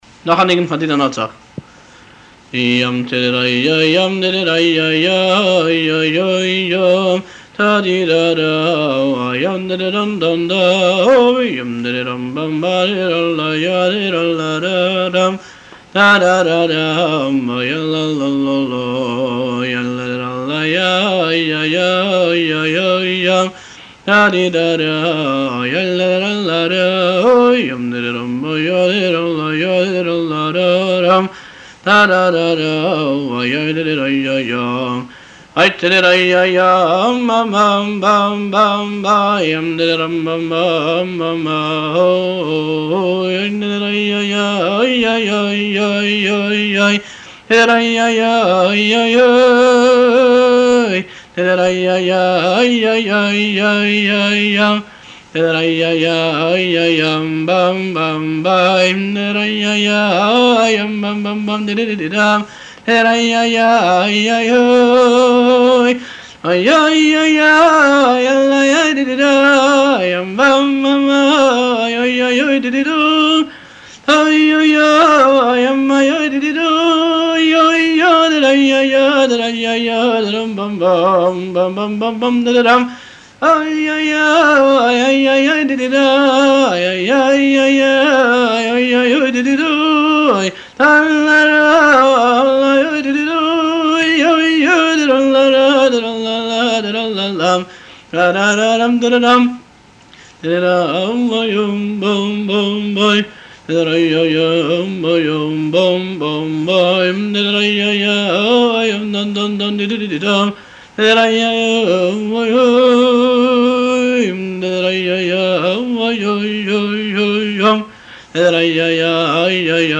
מארש דידן נצח – חב"דפדיה
NSH_054_Didan_Notzach_March.mp3